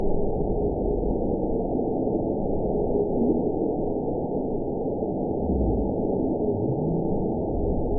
event 919707 date 01/18/24 time 04:02:51 GMT (1 year, 10 months ago) score 9.11 location TSS-AB09 detected by nrw target species NRW annotations +NRW Spectrogram: Frequency (kHz) vs. Time (s) audio not available .wav